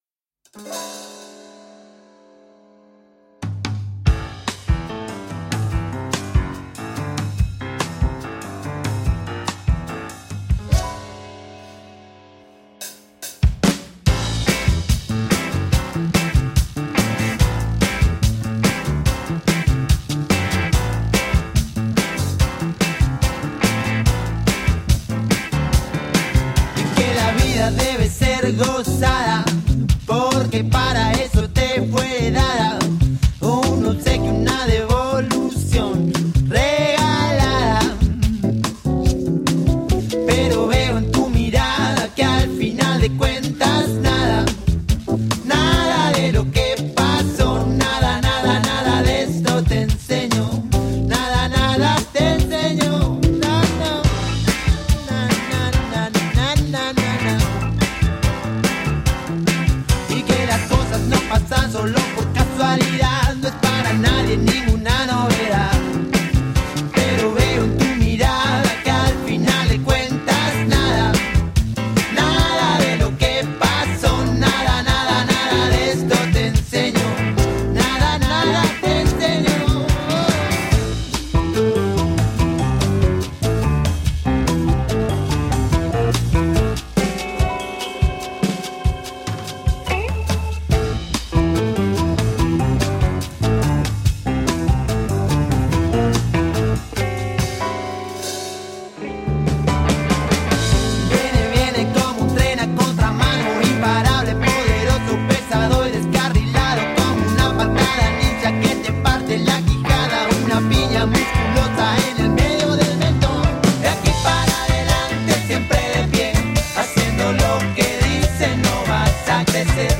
Funk jazz salsa rock from argentina.
Tagged as: Alt Rock, Latin